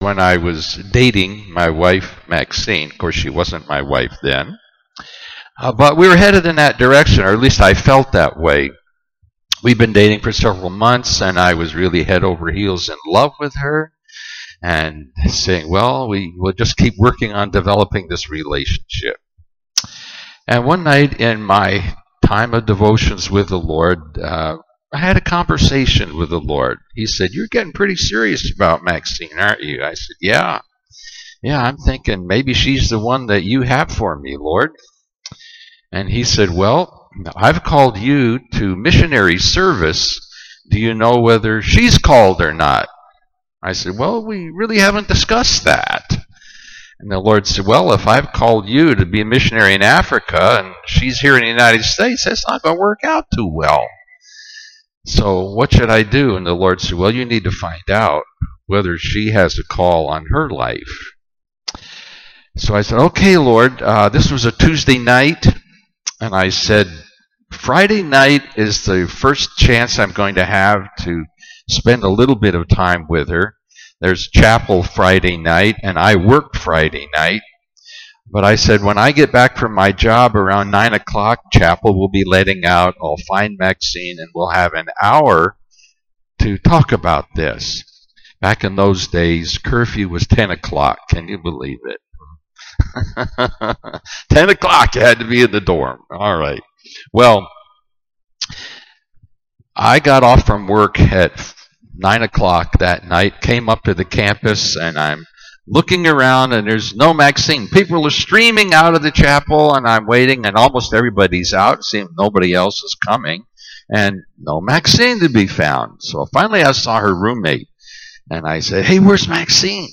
Family Camp 2022